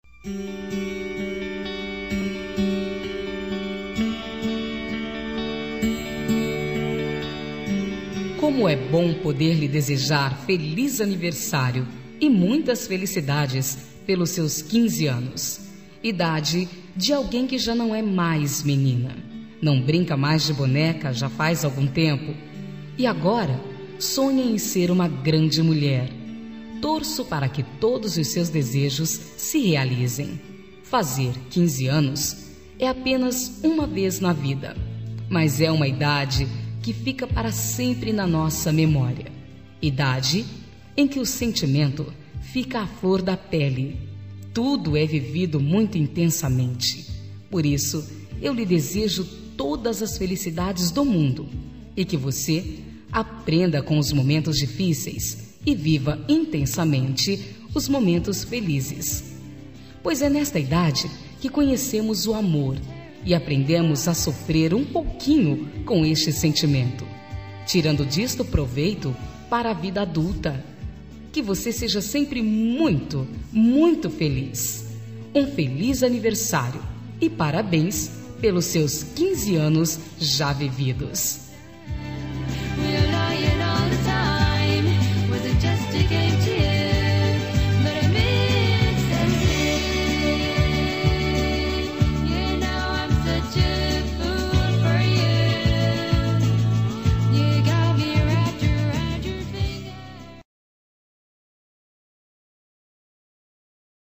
Aniversário de 15 anos – Voz Feminina – Cód: 33371